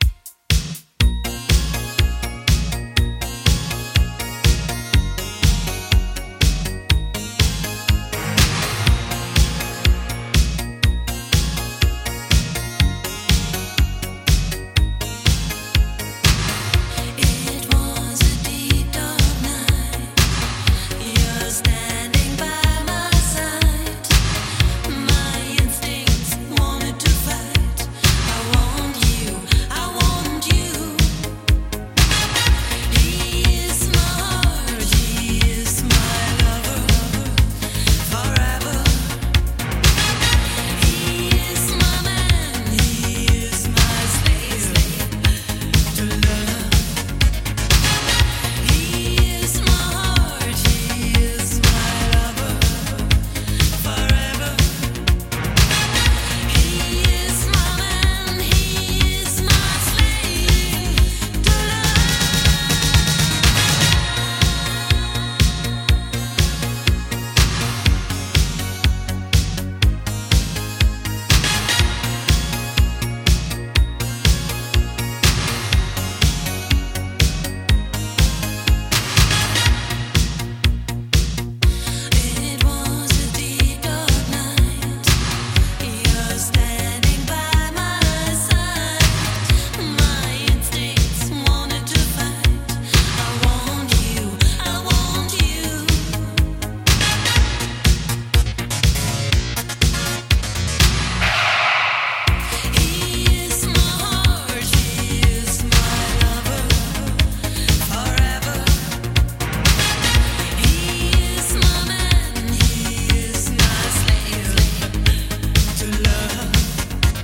デケデケと迫るシンセベースや切なげな歌唱